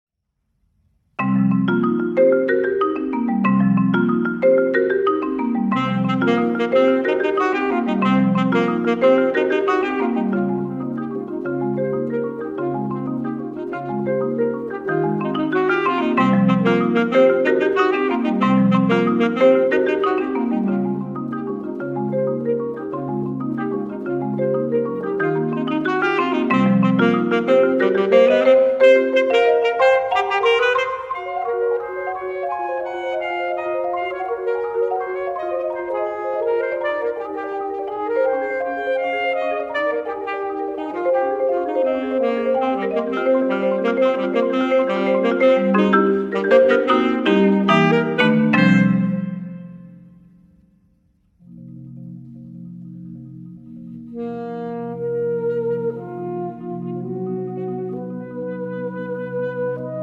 saxophone
marimba